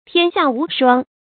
注音：ㄊㄧㄢ ㄒㄧㄚˋ ㄨˊ ㄕㄨㄤ
天下無雙的讀法